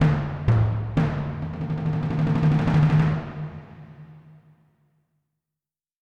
Drum Roll (1).wav